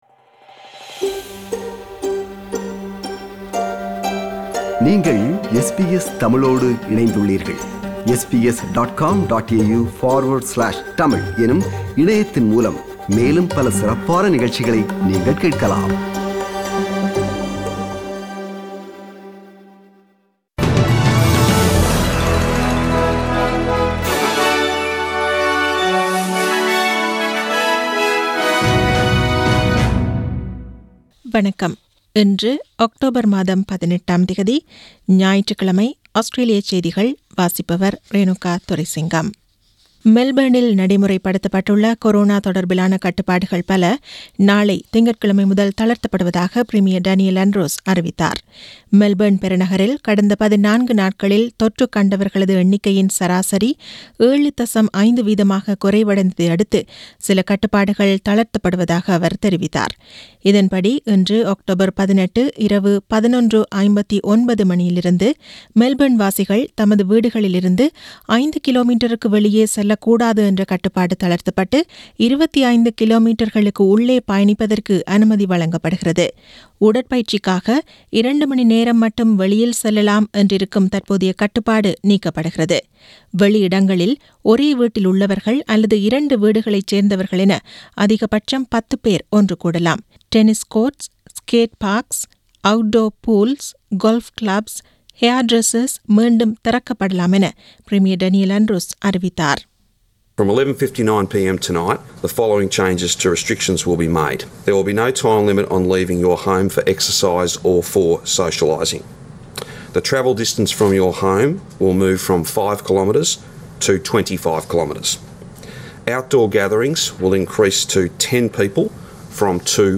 Australian news bulletin for Sunday 18 October 2020.